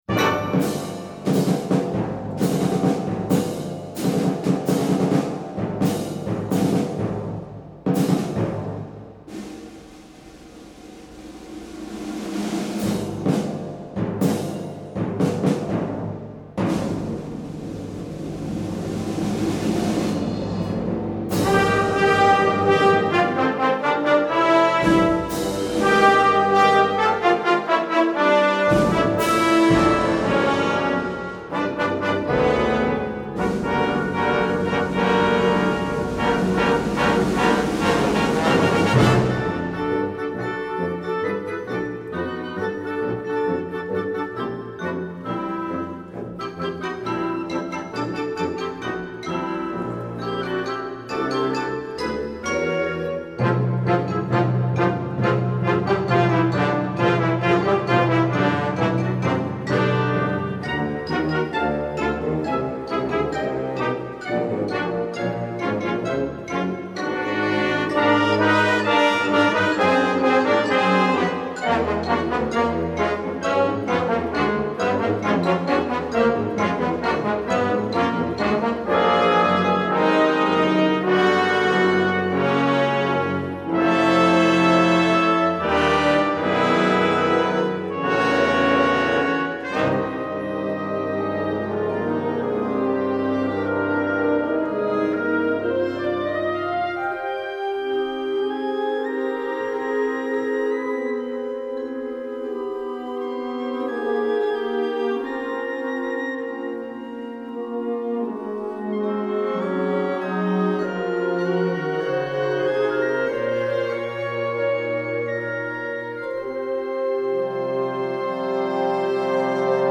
Genre: WInd Orchestra
Percussion 2 (vibraphone, xylophone)
Percussion 4 (snare drum, bass drum)